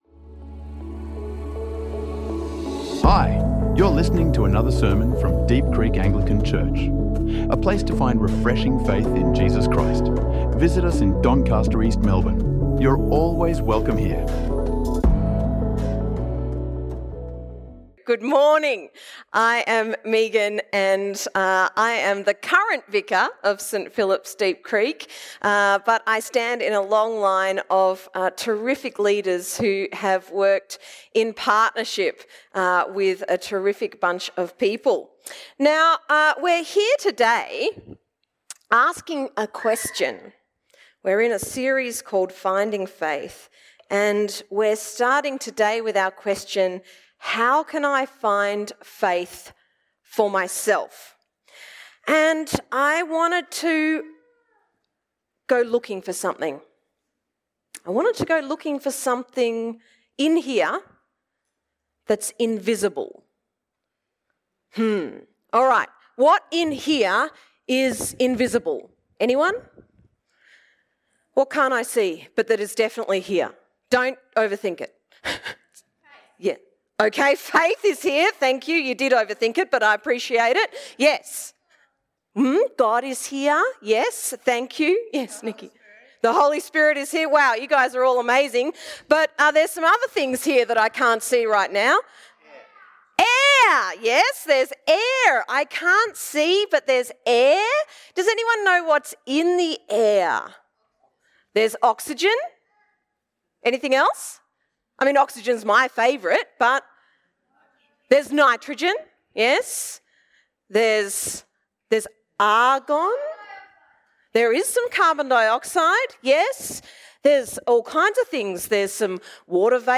This sermon explores how you can discover personal faith in Jesus, moving from questions to trust.